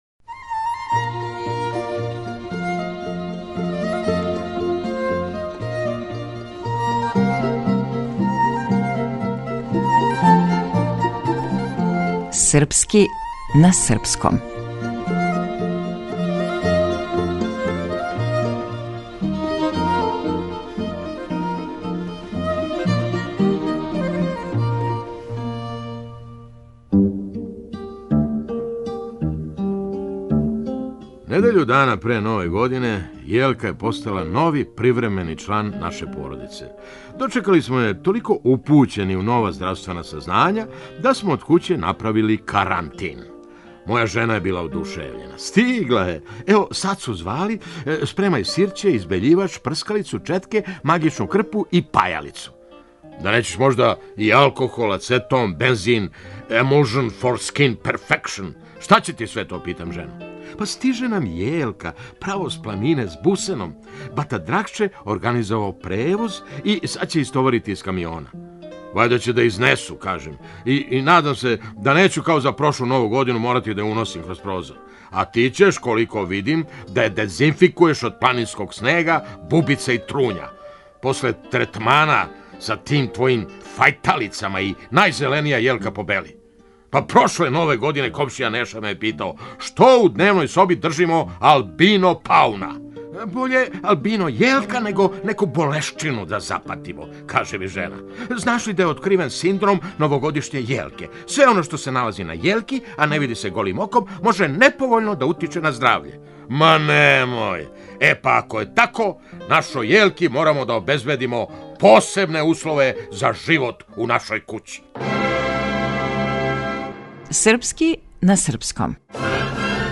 Глумац - Феђа Стојановић